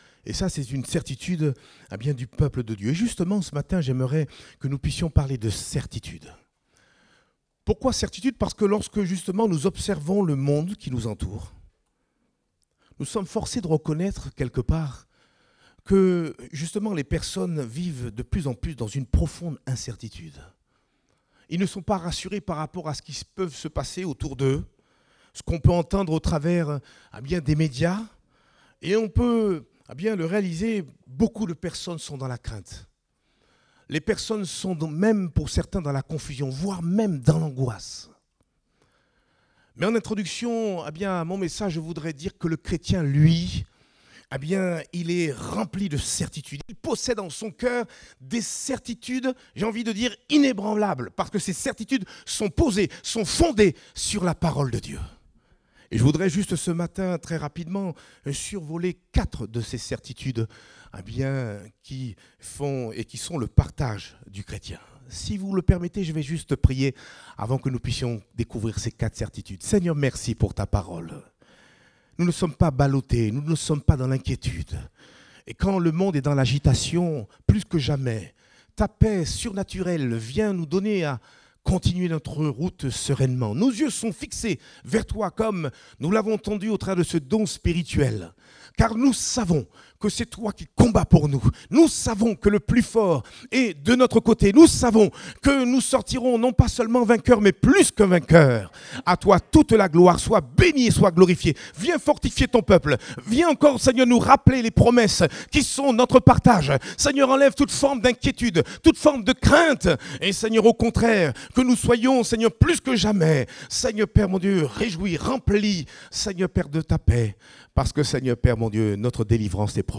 Date : 5 novembre 2023 (Culte Dominical)